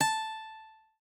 lute_a.ogg